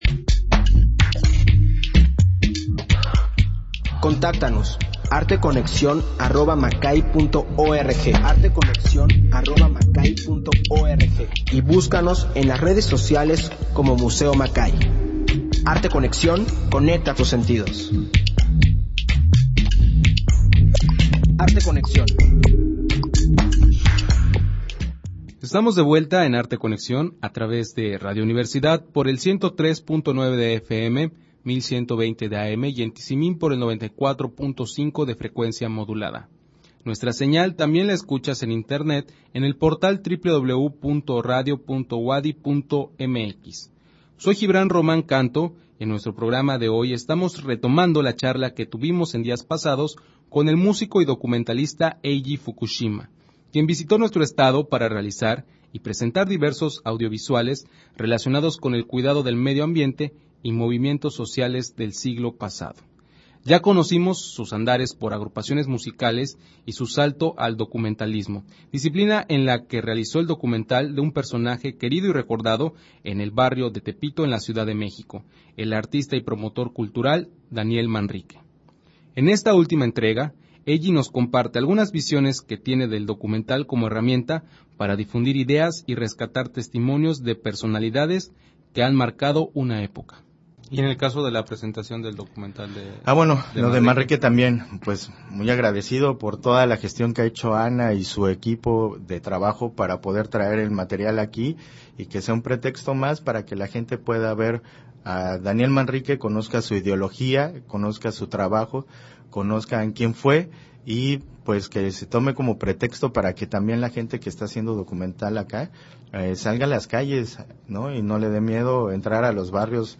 Emisión de Arte Conexión transmitida el 3 de agosto del 2017.